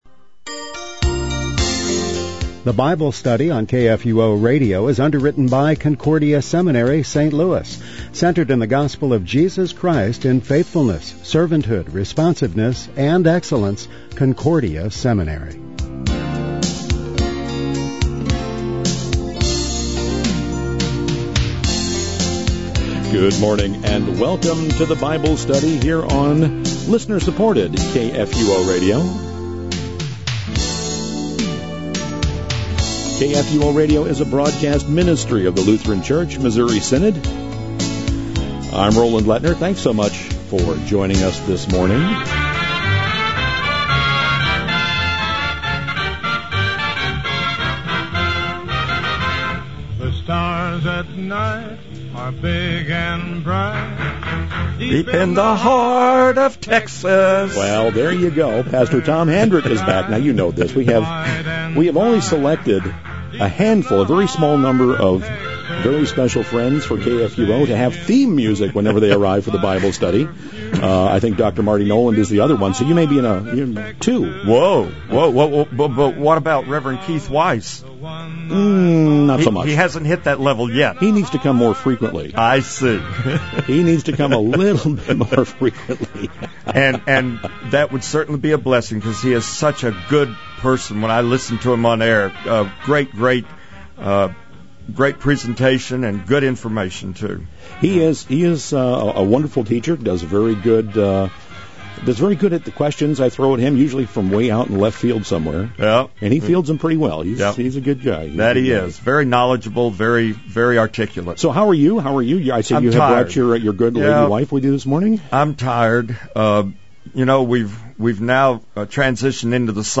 The Bible Study - Exodus 9:8-35